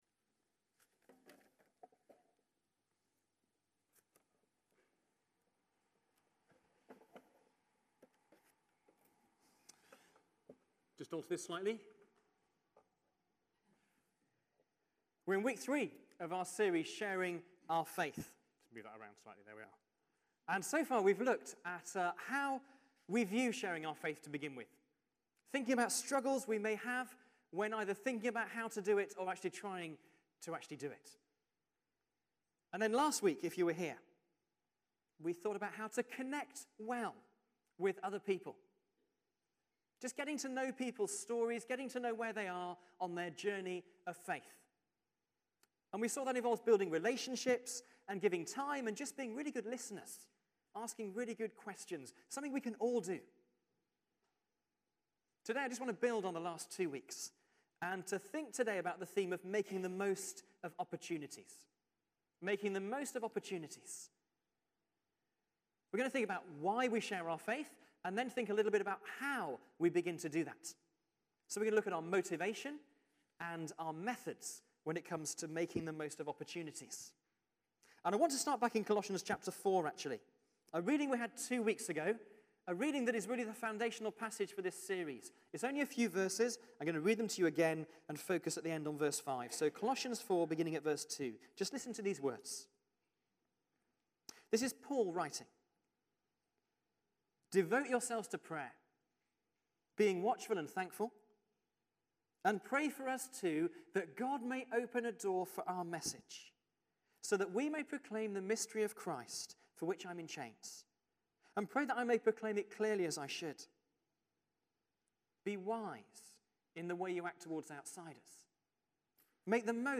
Sermon_16March14.mp3